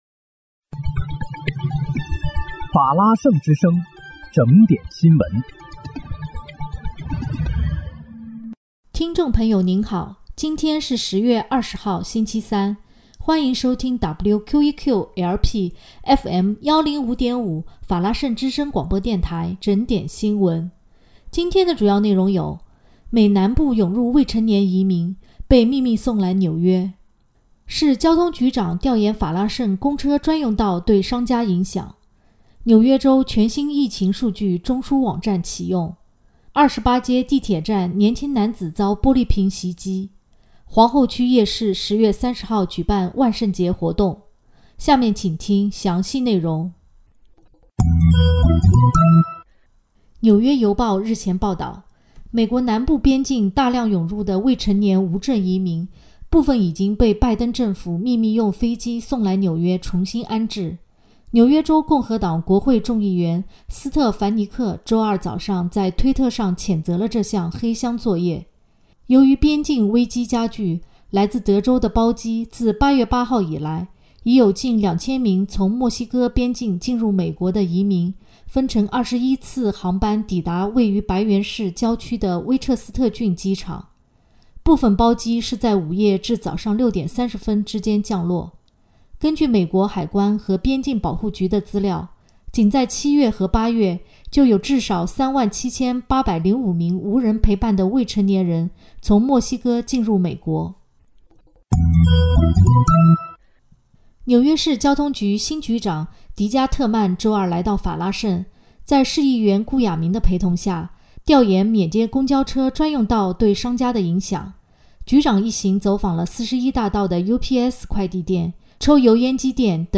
10月20日（星期三）纽约整点新闻